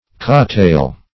cattail \cat"tail\, Cat-tail \Cat"-tail\(k[a^]t"t[=a]l), n.